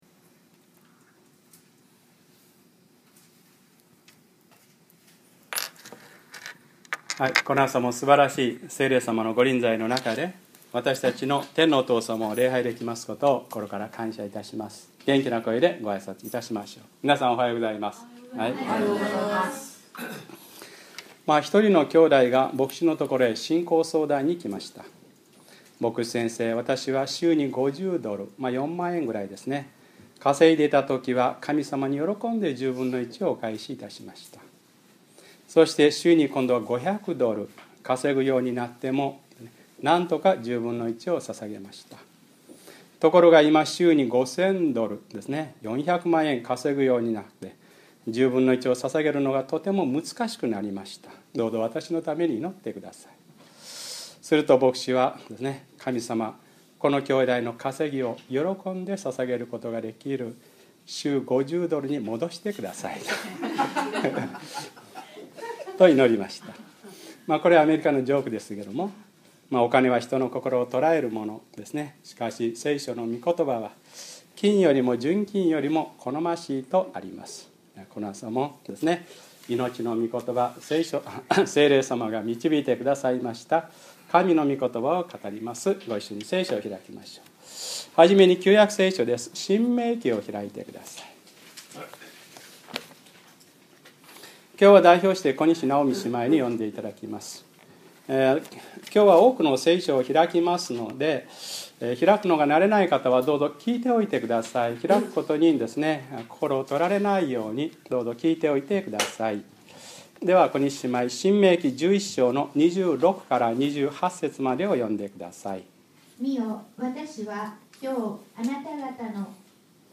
2012年6月3日(日）礼拝説教 『祝福の３原則』